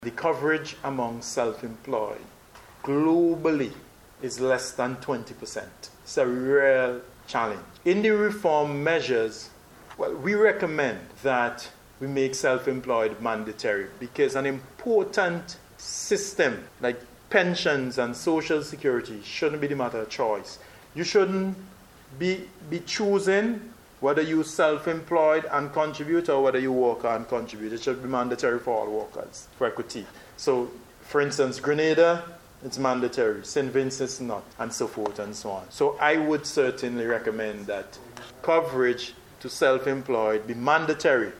Mr. Haynes made the statement while speaking at a recent media consultation hosted by the NIS.